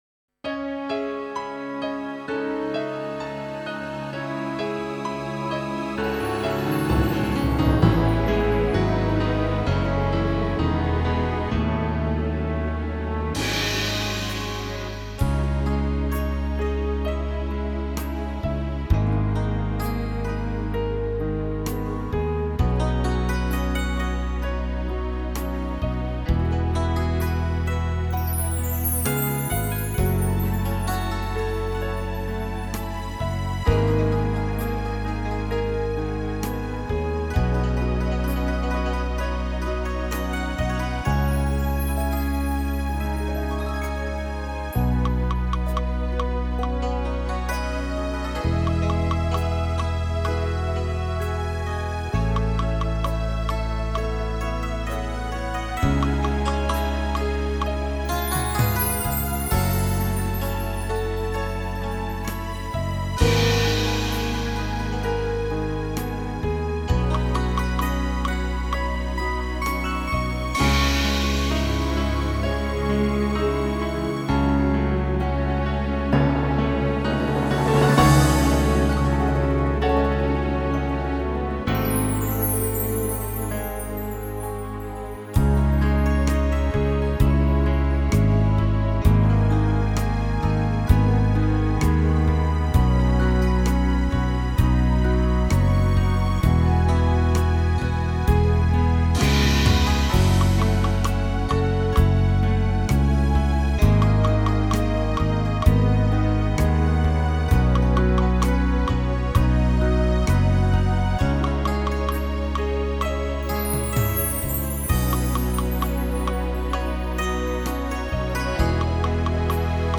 •   Beat  01.
(C#m) 3:56